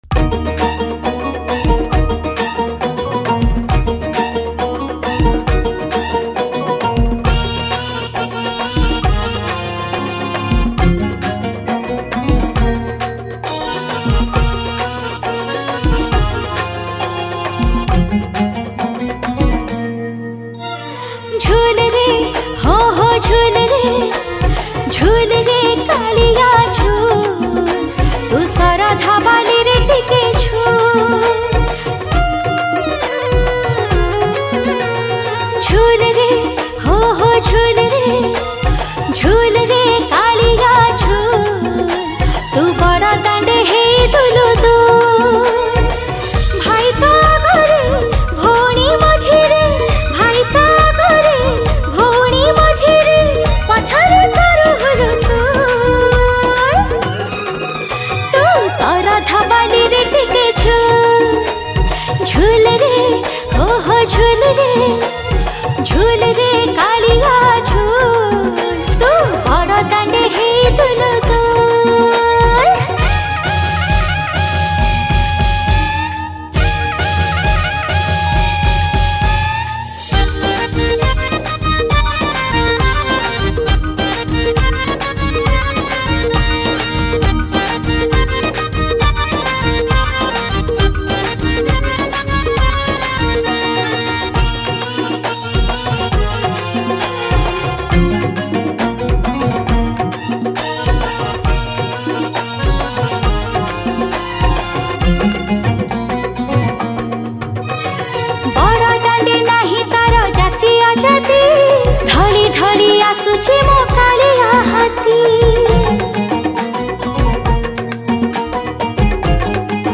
Ratha Yatra Odia Bhajan 2022